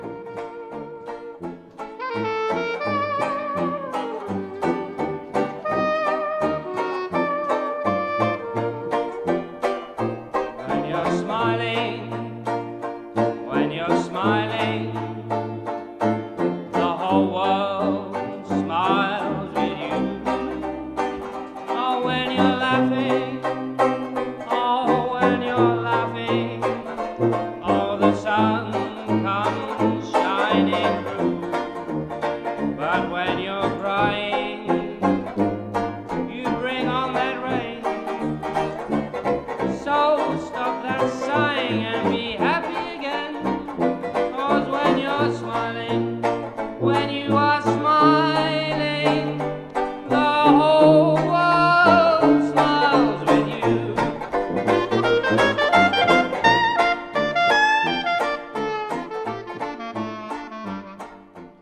• Versatile, genre-hopping jazz ensemble
• Traditional jazz but with a modern twist
• Inspired by '20s/'30s New Orleans music